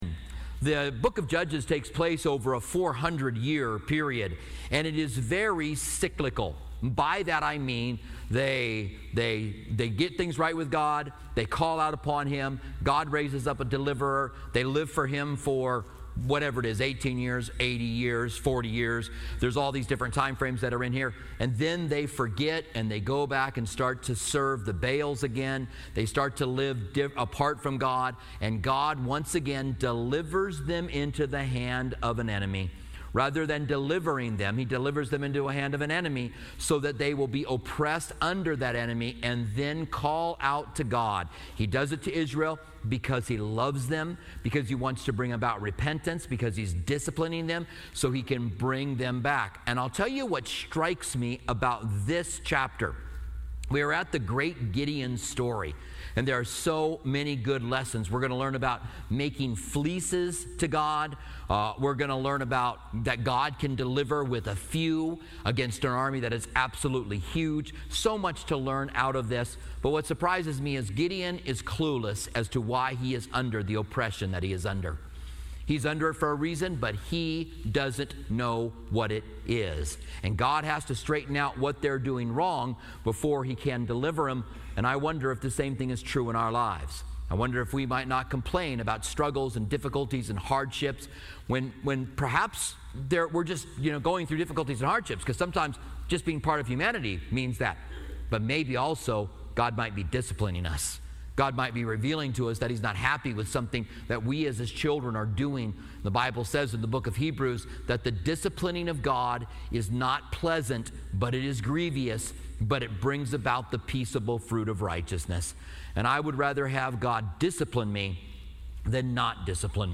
Commentary on Judges